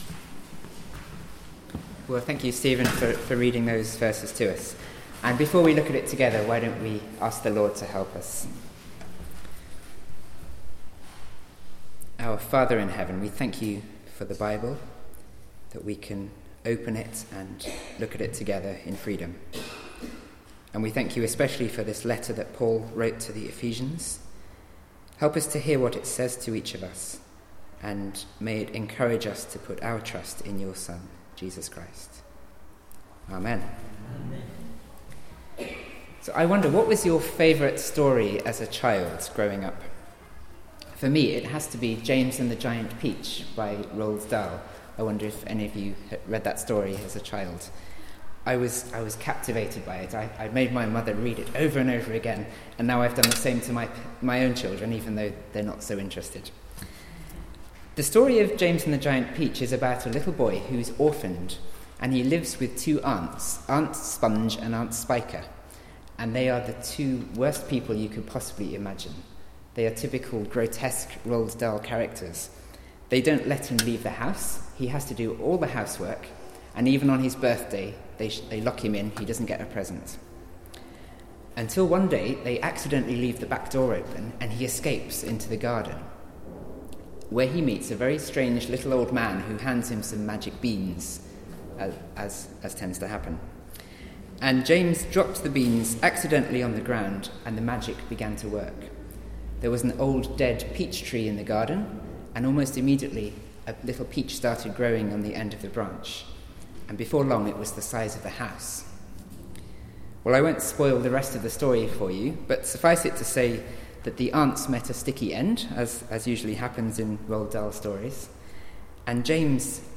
Passage: Ephesians 2 :1-10 Service Type: Weekly Service at 4pm Bible Text